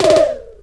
SINGLE HITS 0025.wav